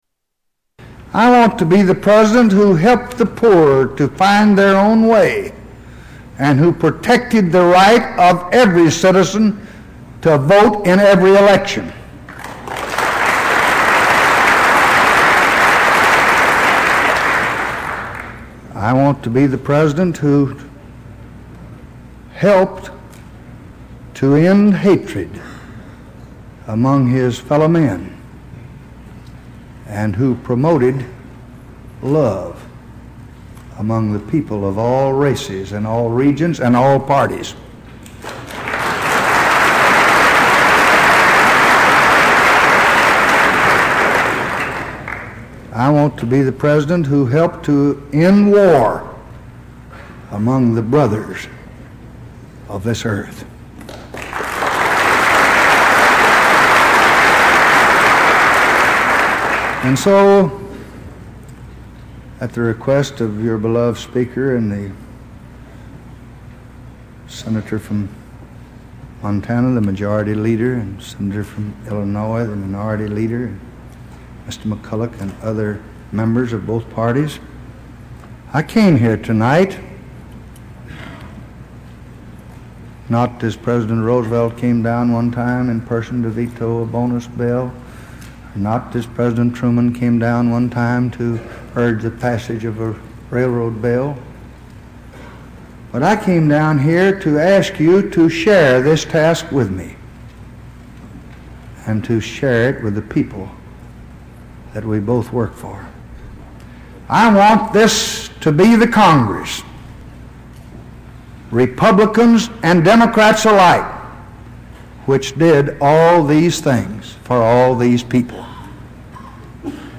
Tags: Historical Lyndon Baines Johnson Lyndon Baines Johnson clips LBJ Renunciation speech